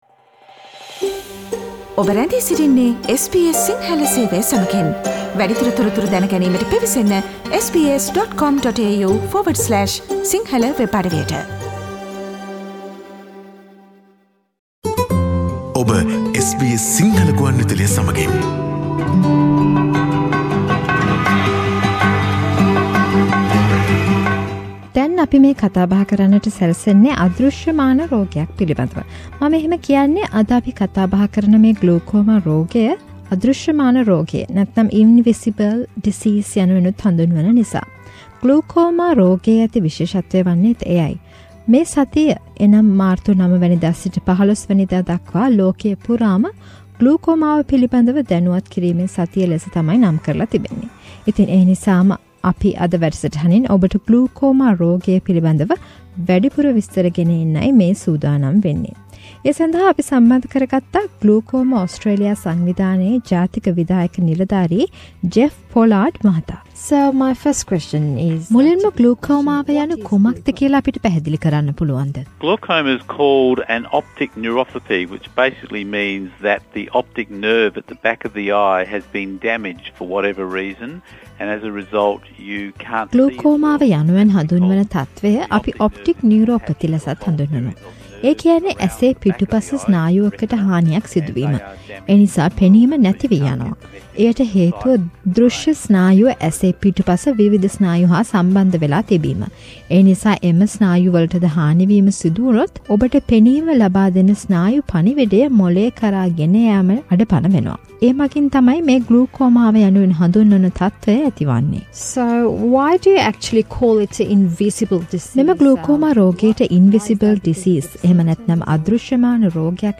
ග්ලූකෝමා රෝගය පිළිබඳ දැනුවත් කිරීමේ සතිය මාර්තු මස 9 දා සිට 15 දා දක්වා ක්‍රියාත්මකයි. ඒ නිමිත්තෙන් SBS සිංහල සේවය Glaucoma Australia ආයතනය සමග කළ මේ කෙටි සාකච්ඡාවට සවන්දෙන්න